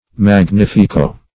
Search Result for " magnifico" : Wordnet 3.0 NOUN (1) 1. a person of distinguished rank or appearance ; The Collaborative International Dictionary of English v.0.48: Magnifico \Mag*nif"i*co\, n.; pl. Magnificoes .